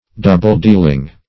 \dou"ble-deal"ing\